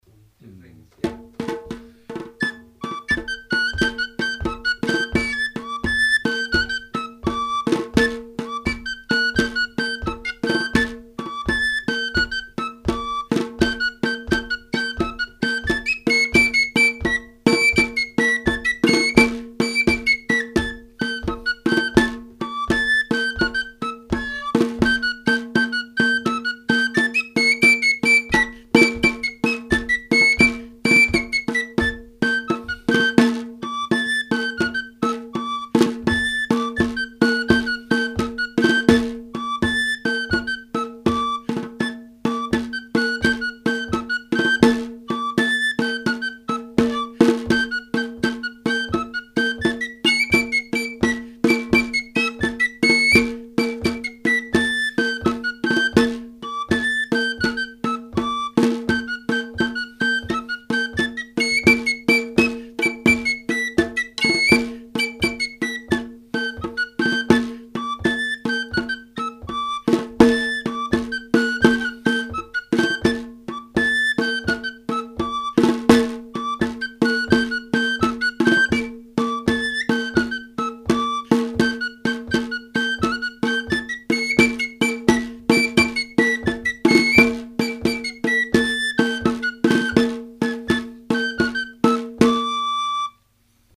Foresters music
Accordion